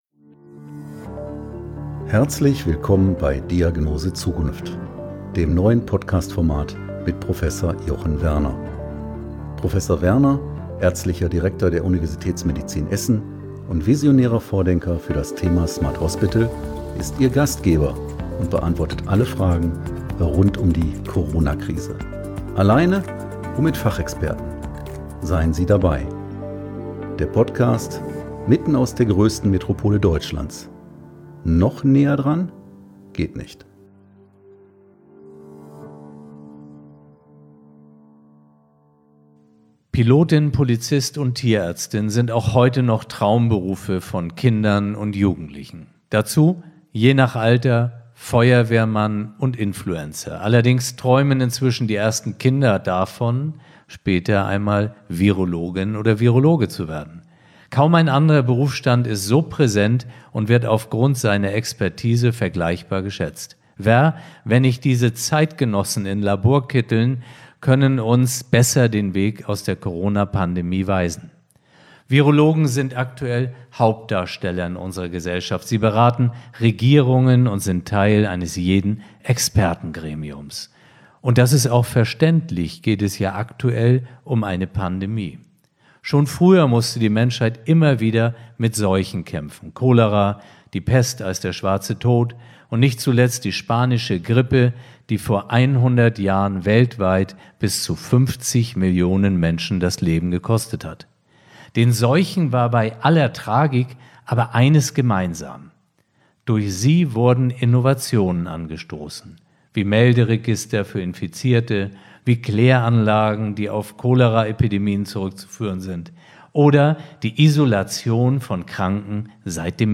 Wir beleuchten sehr detailliert die Unterschiede zwischen diesem Corona-Virus und anderen Corona Viren, wie z.B. dem SARS-CoV oder MERS. Wir sprechen über Behandlungsansätze aber auch darüber wie man Covid-19 so in den Griff bekommt, dass wir nächstes Jahr nicht vor den gleichen Herausforderungen stehen wie in 2020. Ist eine Herdenimmunität dabei die Lösung?